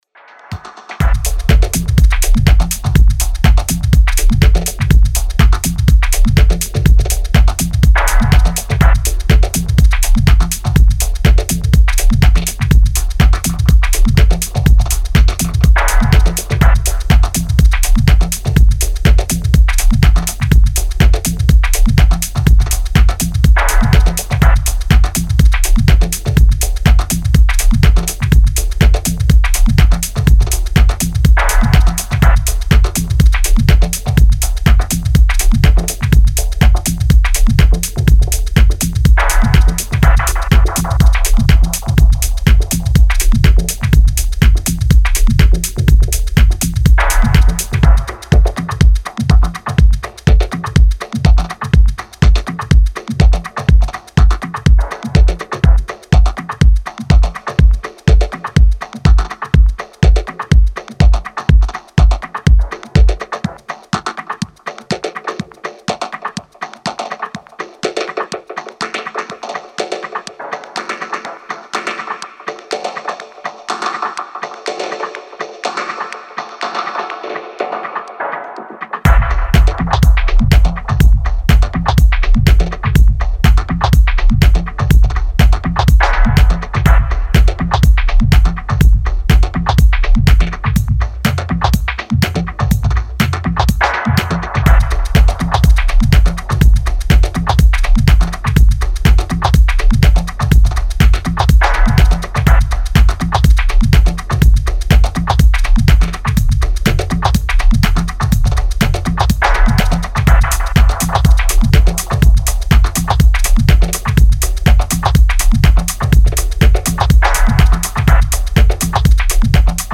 Style: Techno / Dub / Tech House